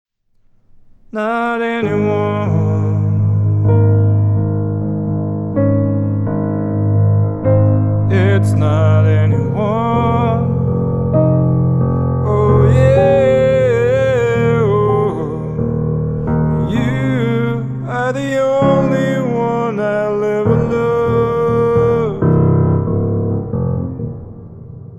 Как будто на телефон записано..